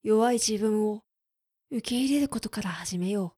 クール男性
dansei_yowaizibunwoukeirerukotokarahazimeyou.mp3